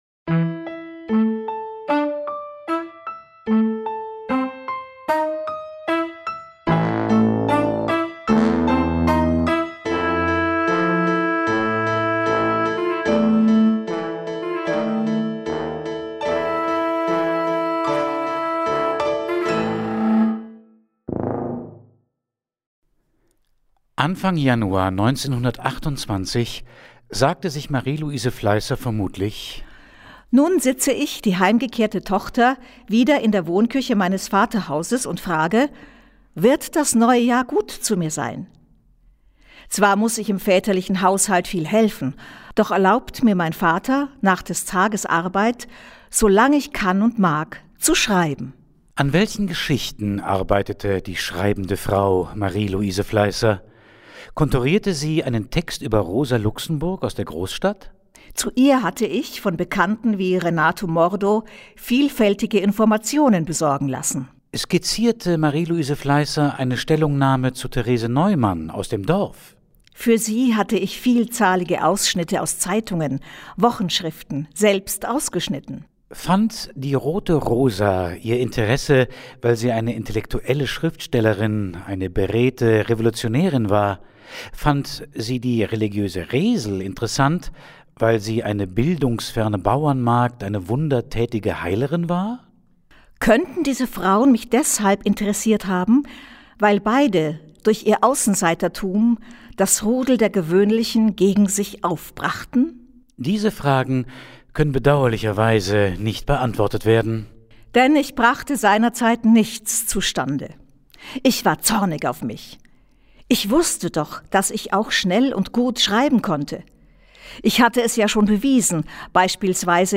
Arrangement aus Äußerungen von Zeitgenossen zu bzw. über Marieluise Fleißer
musikalisch aufgelockert durch eine